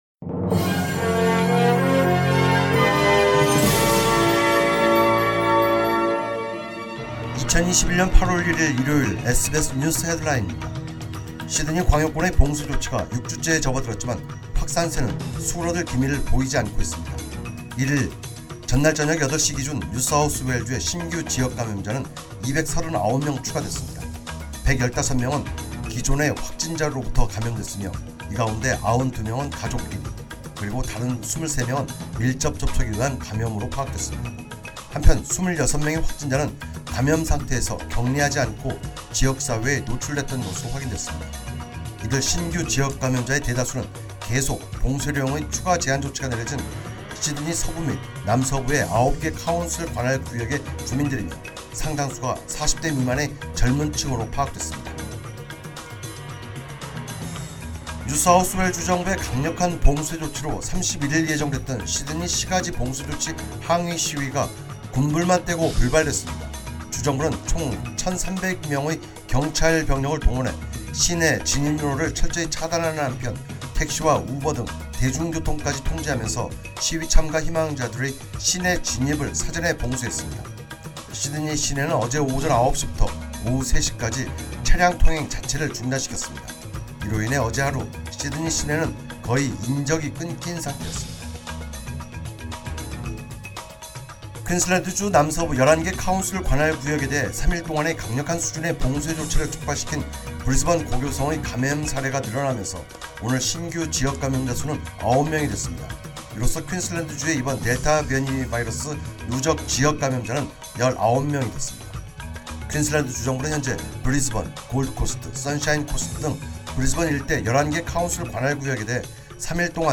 2021년 8월 1일 일요일 SBS 뉴스 헤드라인입니다.